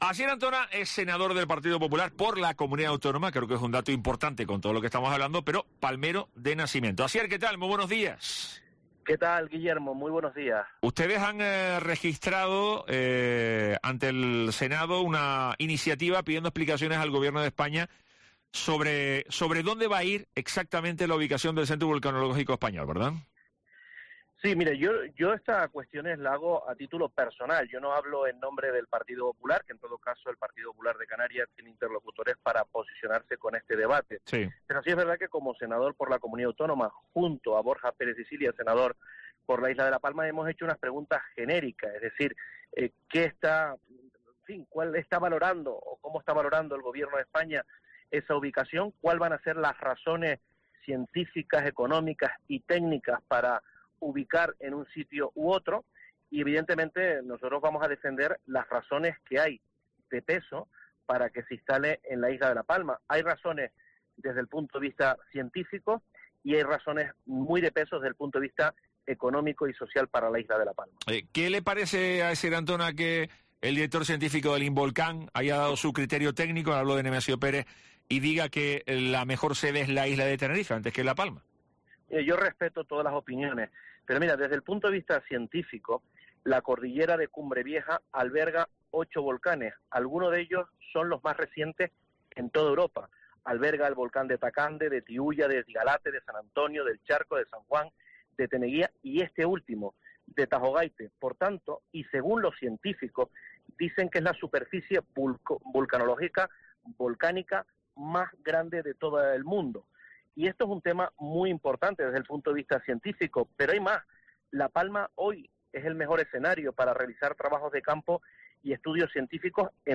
Entrevista Asier Antona